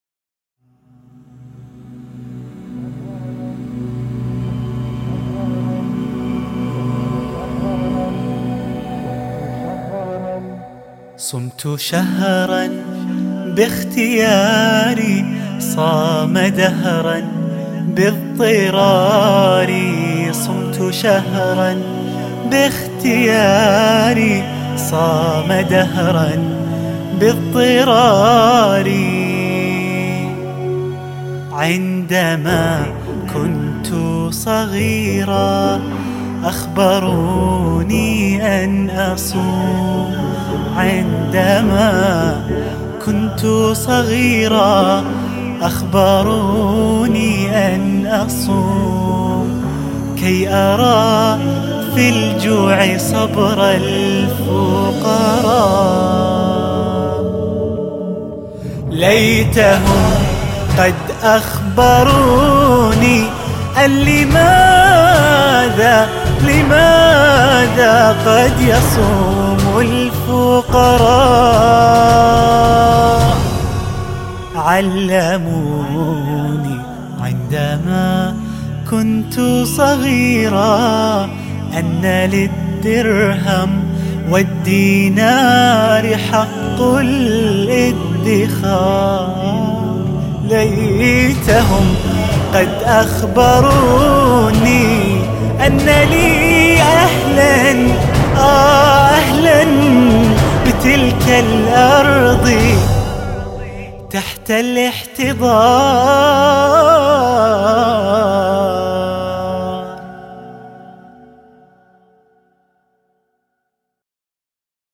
تفاصيل نشيد